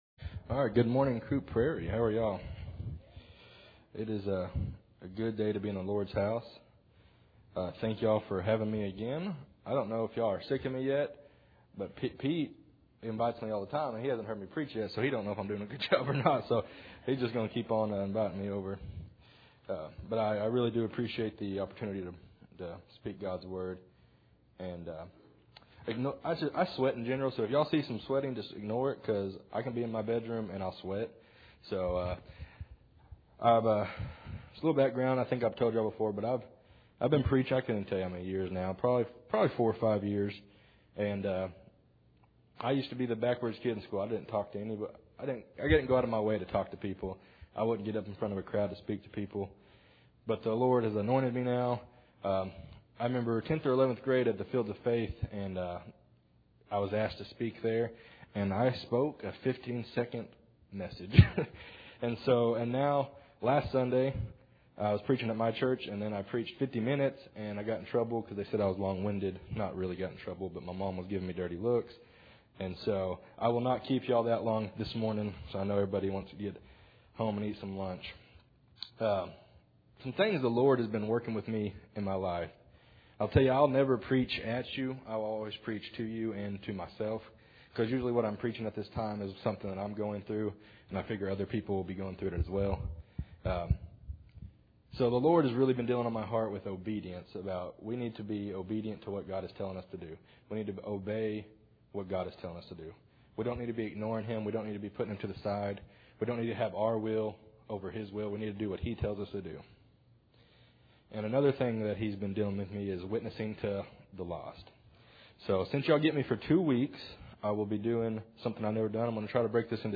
Matthew 22:34-40 Service Type: Sunday Morning Bible Text